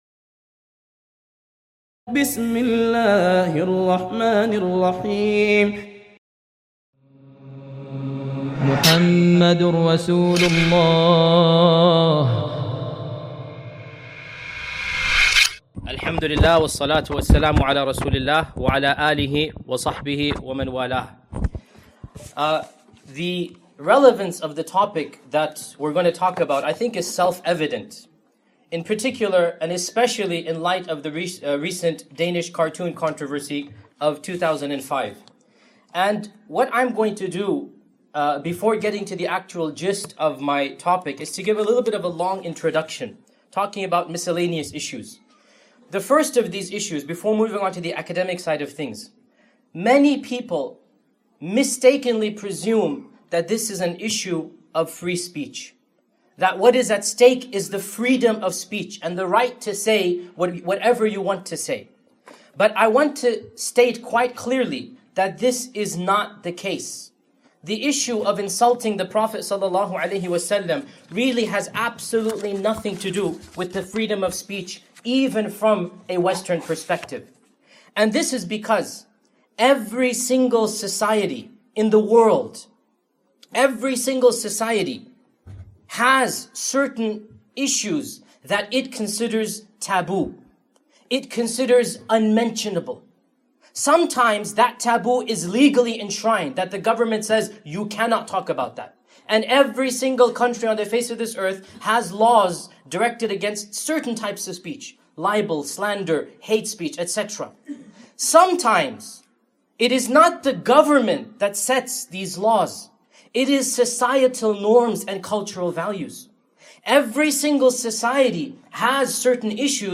It is our goal to address a series of such incidents from the Seerah and explain the one of the best methodologies in defending the honor of our Prophet (SAW). Recorded on Saturday, August 30th, 2008 at the 45th Annual ISNA Convention in Columbus, OH.